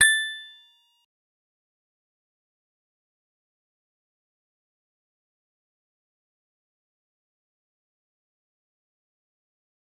G_Musicbox-A7-mf.wav